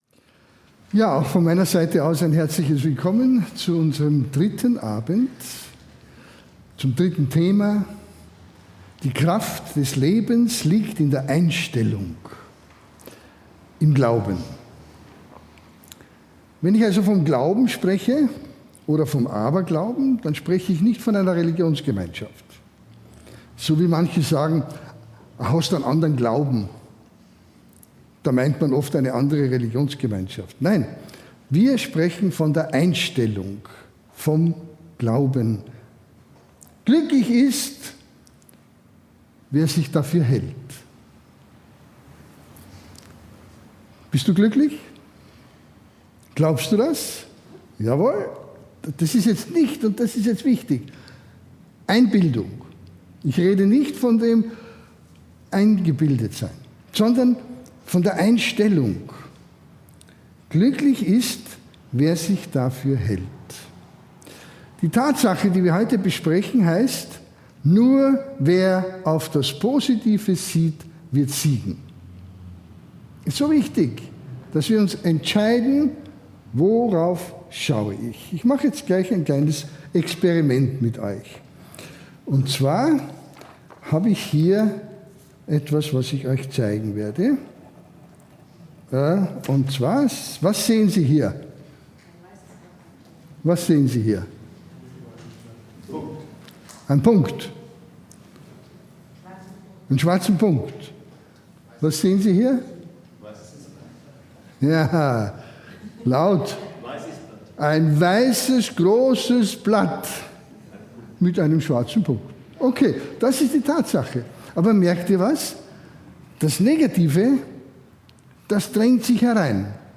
In diesem inspirierenden Vortrag wird die Kraft des Glaubens und der positiven Einstellung betont. Verborgene Möglichkeiten der Selbstentfaltung werden erkundet, während die Zuhörenden lernen, ihre Sichtweise zu verändern und Herausforderungen mit Optimismus zu begegnen.